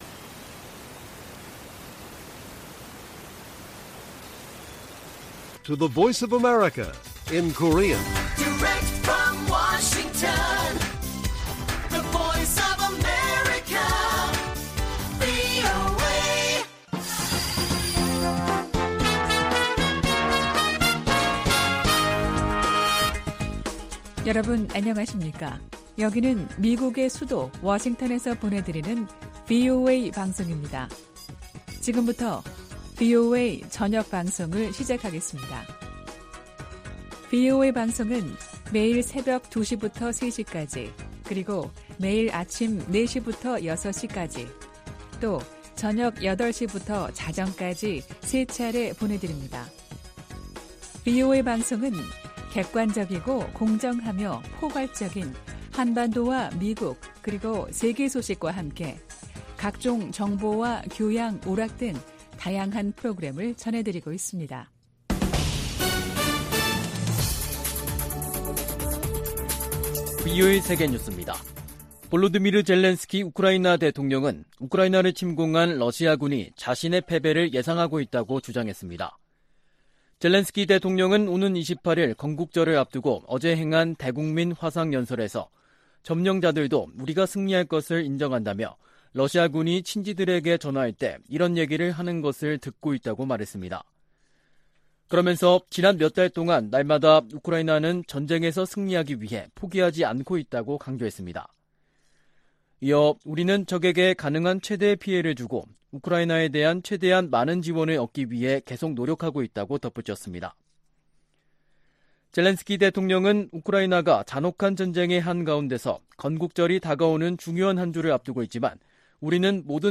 VOA 한국어 간판 뉴스 프로그램 '뉴스 투데이', 2022년 7월 25일 1부 방송입니다. 미 국무부의 빅토리아 눌런드 정무차관은 러시아와 북한의 밀착이 러시아의 고립을 보여주는 것이라고 평가했습니다. 일본과 아일랜드 정상이 북한의 탄도미사일 발사를 규탄하며 대량살상무기 완전 폐기를 촉구했습니다. 사이버 공격 대응을 위한 정부의 노력을 강화하도록 하는 ‘랜섬웨어 법안’이 미 하원 상임위원회를 통과했습니다.